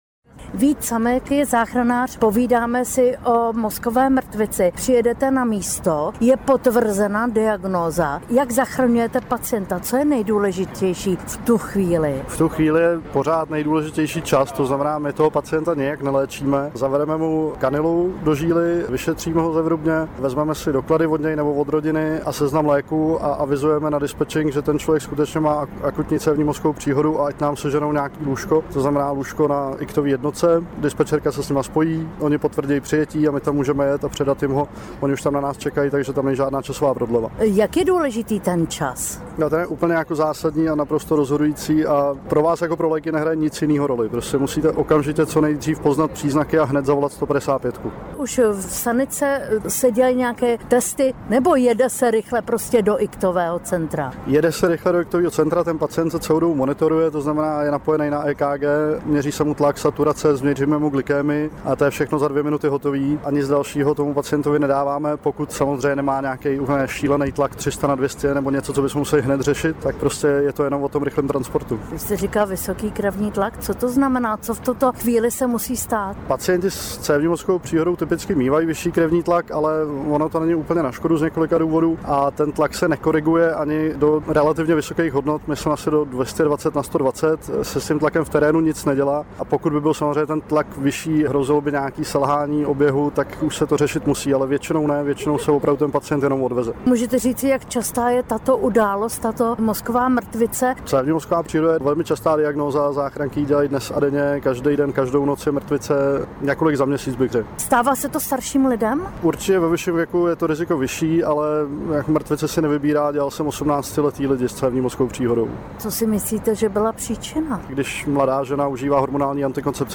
AUDIO rozhovor: Jak poznat první příznaky mrtvice a co v takové případě dělat?
čas-je-mozek-záchranář.mp3